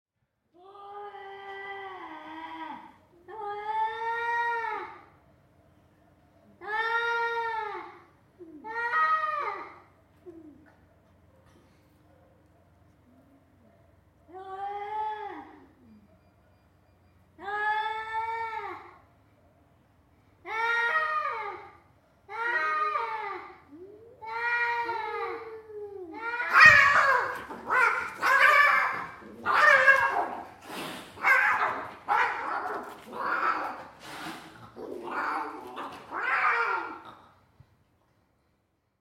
The Medina of Tunis is a sensory labyrinth — a bustling marketplace during the day and a quiet, mysterious maze by night. During one nocturnal stroll, the eerie cry of what sounded like a baby echoed through the narrow alleys.
It turned out to be a fierce territorial battle between two cats, their voices amplified by the silence.
——————— This sound is part of the Sonic Heritage project, exploring the sounds of the world’s most famous sights.